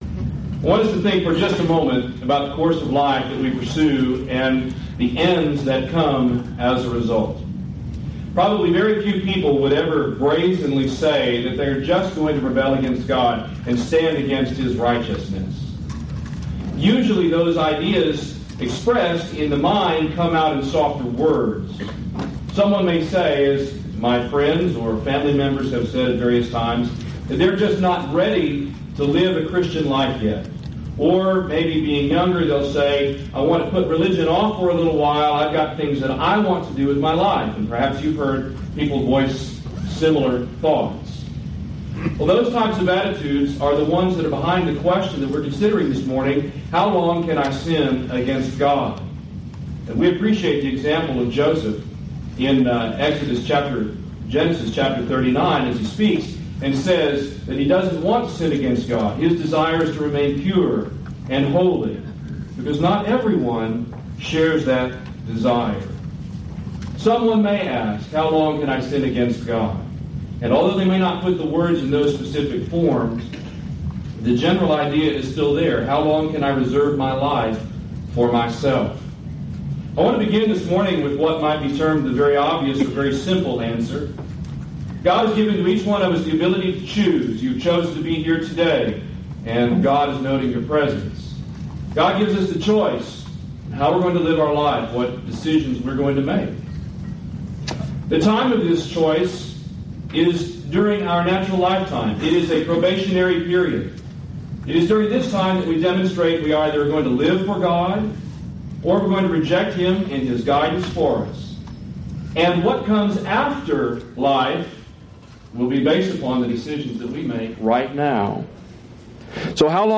Event: 2003 Annual Shenandoah Lectures
lecture